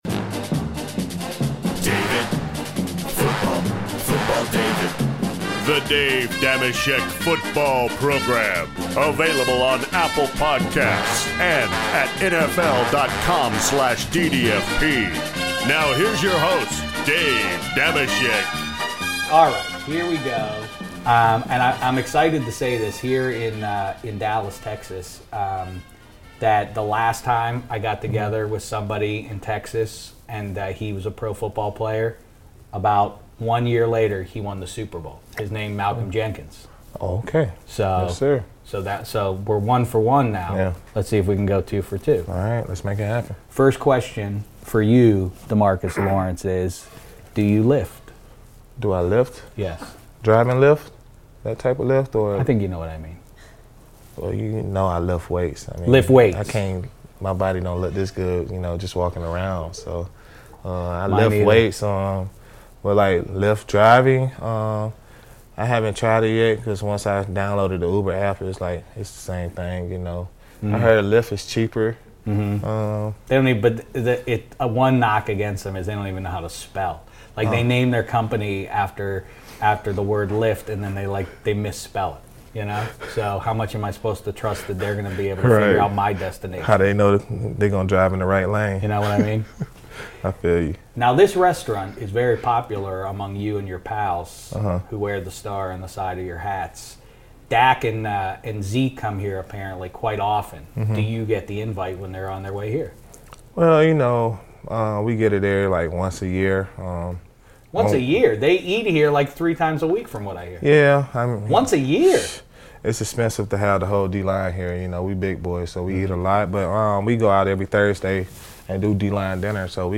Dave Dameshek sits down with Dallas Cowboys DE DeMarcus Lawrence at Nick & Sam's restaurant in Dallas, Texas to discuss all things Cowboys over a huge tomahawk steak dinner.